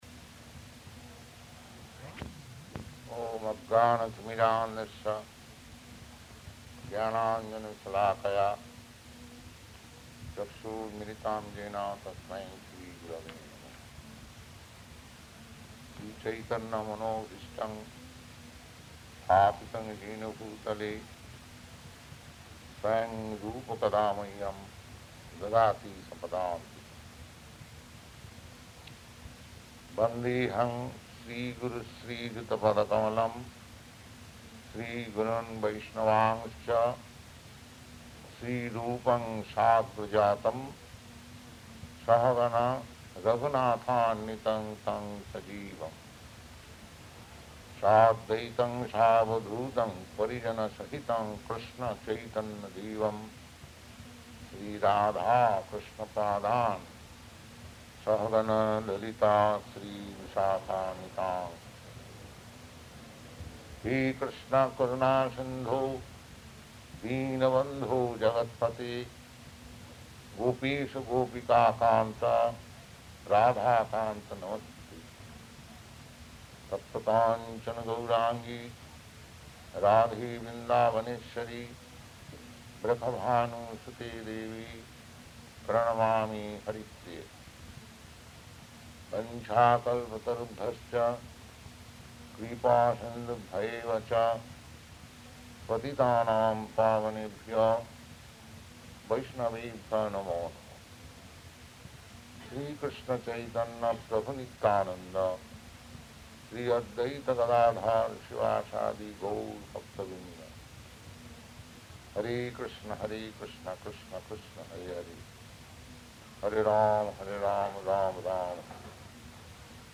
Location: Montreal
[chants maṅgalacaraṇa prayers]